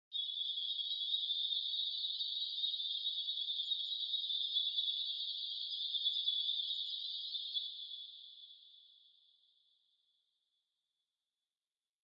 热带鸟类 " 红嘴鹎
描述：红嘴鹎的两首短歌。用Zoom H2.
标签： 鸟舍 白头翁 异国情调 丛林 热带雨林 鸣禽 热带 动物园
声道立体声